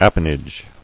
[ap·a·nage]